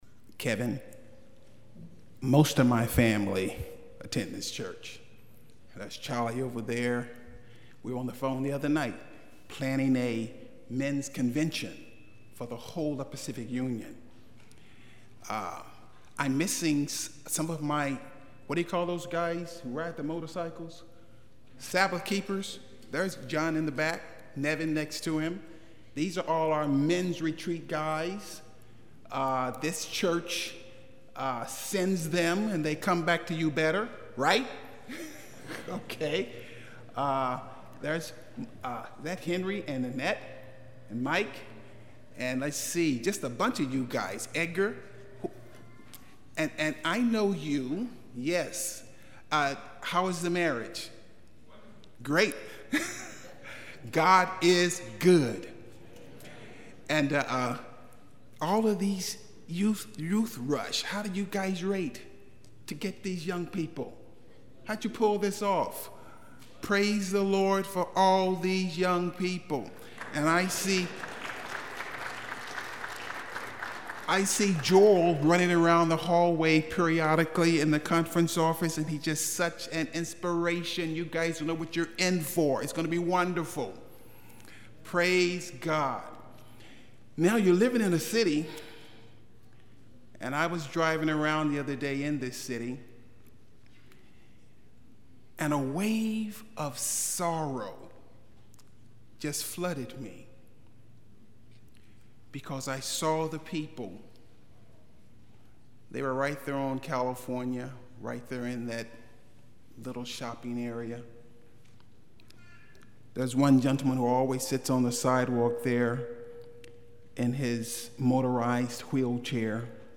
Sabbath Sermons Download Other files in this entry